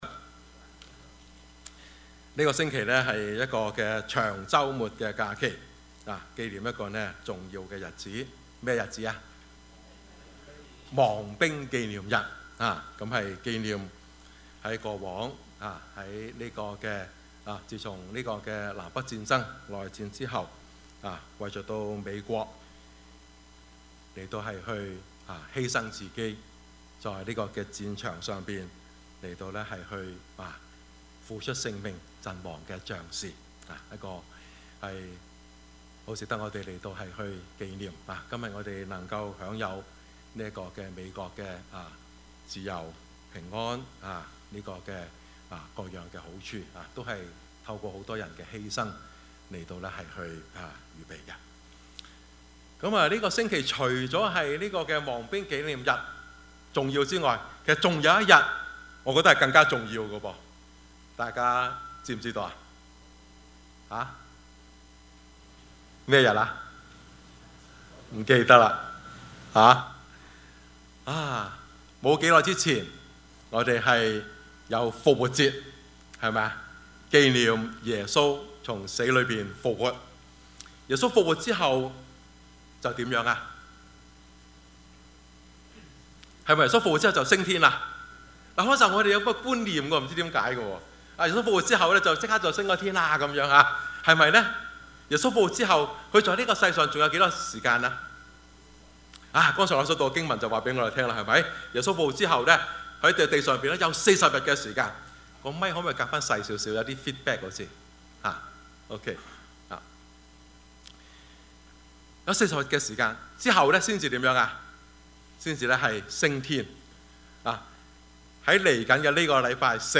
Service Type: 主日崇拜
Topics: 主日證道 « 團契與關懷 錢學森 »